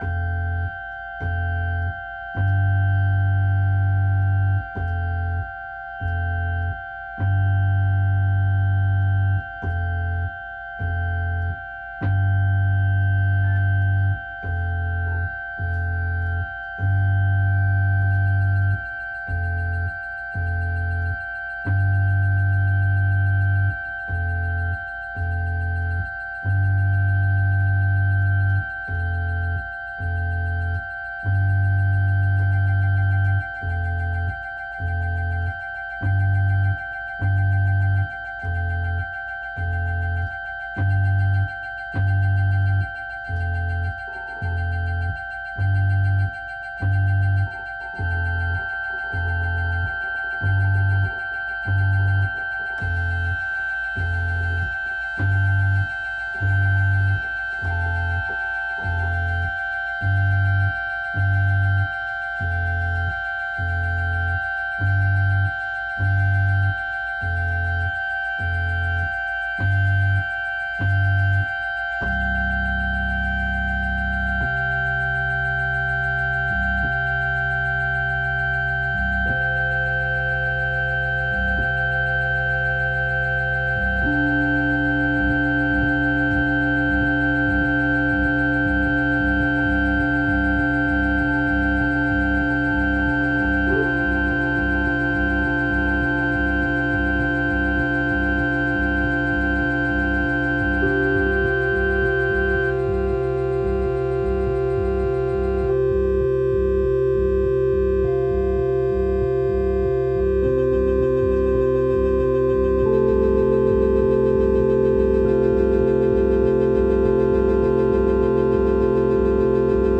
Noisy and sloppy but you might be able to hear some of the goodness hidden in there.